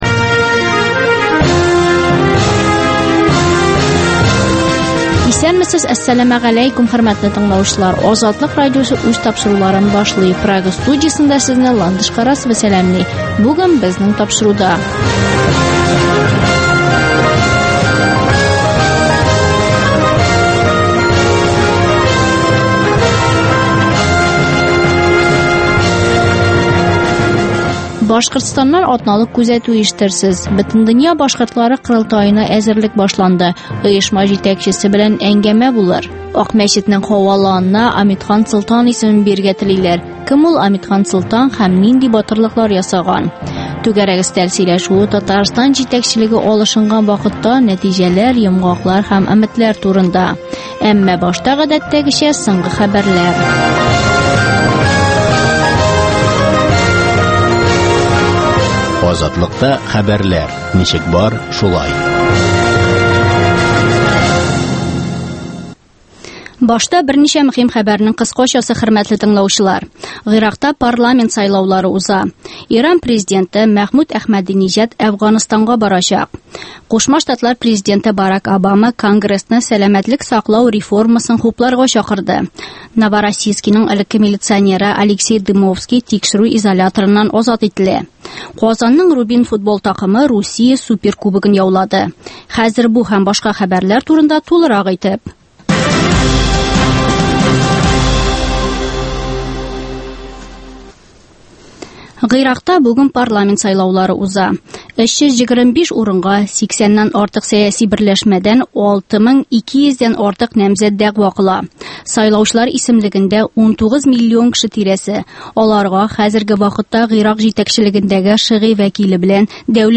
Азатлык узган атнага күз сала - соңгы хәбәрләр - башкортстаннан атналык күзәтү - татар дөньясы - түгәрәк өстәл сөйләшүе